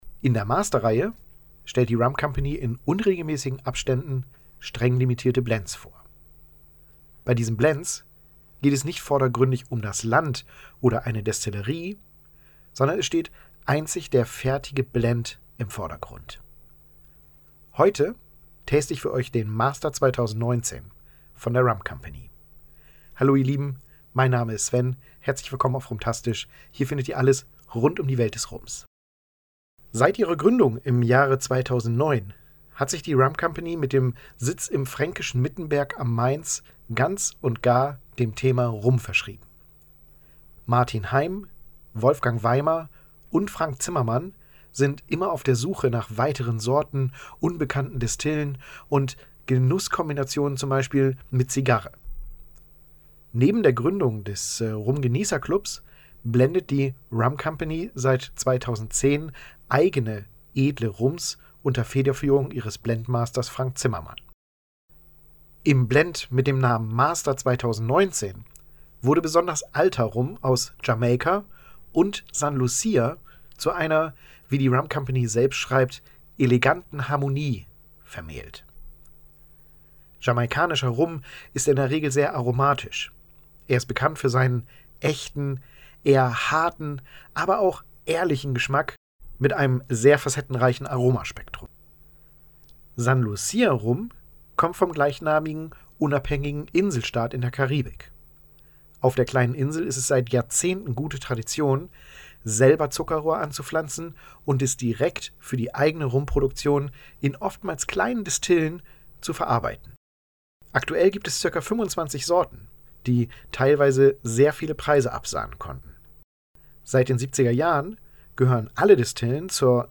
Hier findest Du das Tasting-Video dieses Rums als Audio-Podcast.